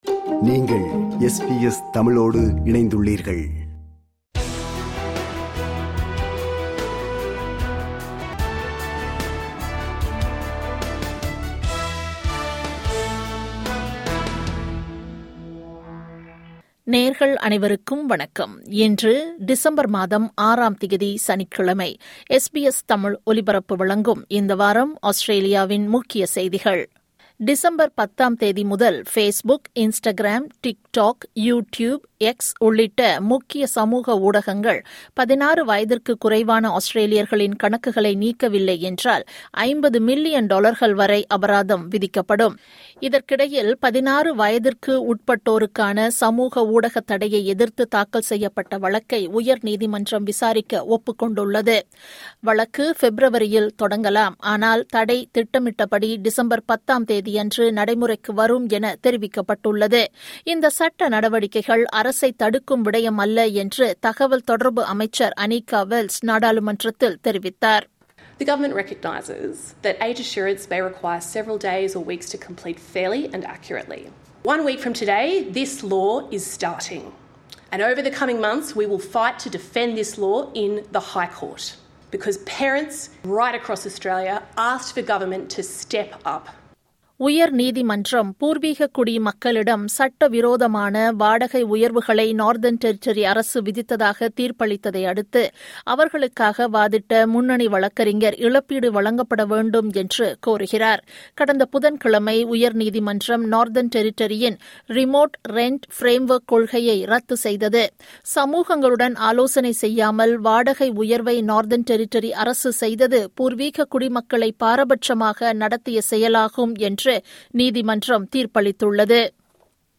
இந்த வார ஆஸ்திரேலிய செய்திகள் (30 நவம்பர் – 6 டிசம்பர் 2025)